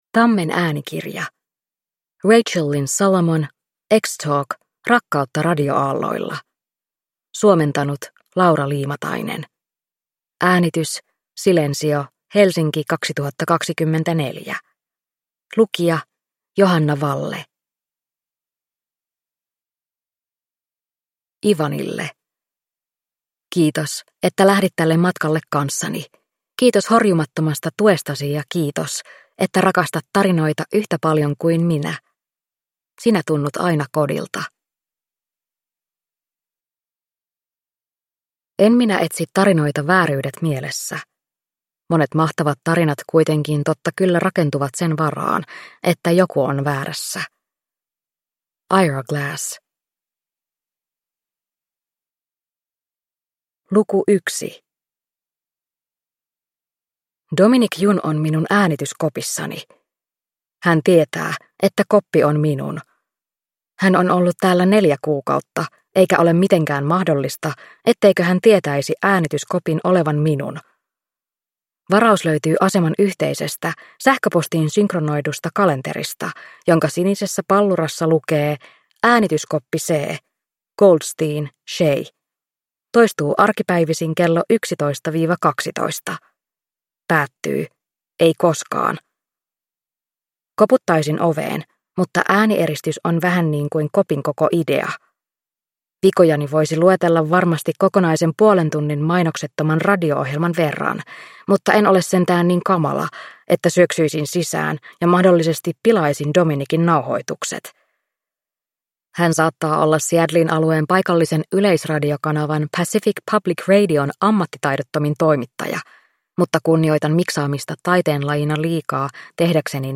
Ex Talk - rakkautta radioaalloilla (ljudbok) av Rachel Lynn Solomon